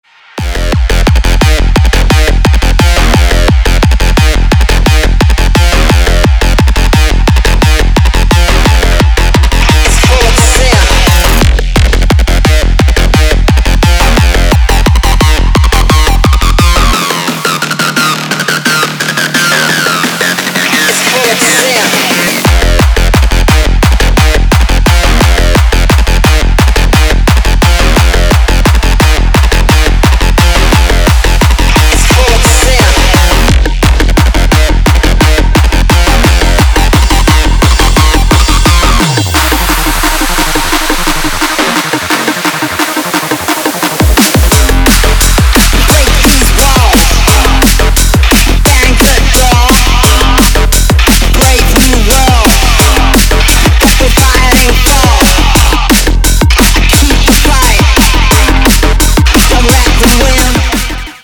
Бодрая нарезка для рингтона